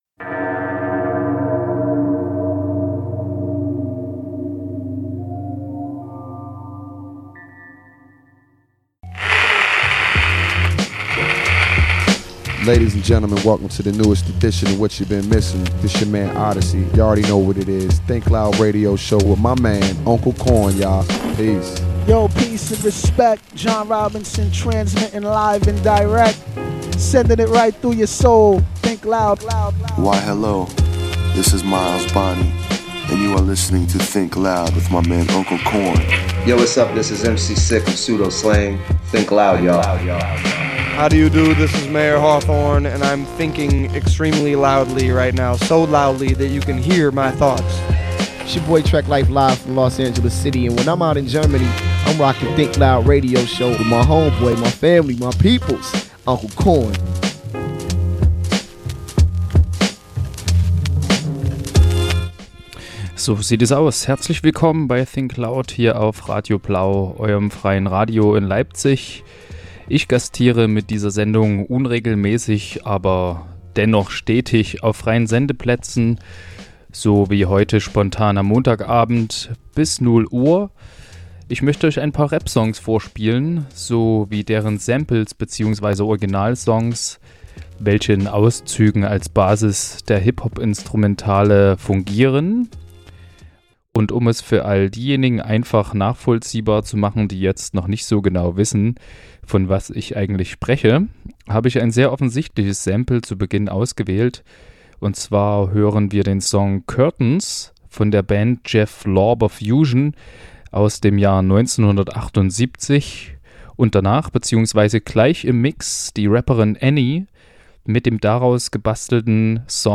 entspannte HipHop-Musik und deren gesampelten Originale.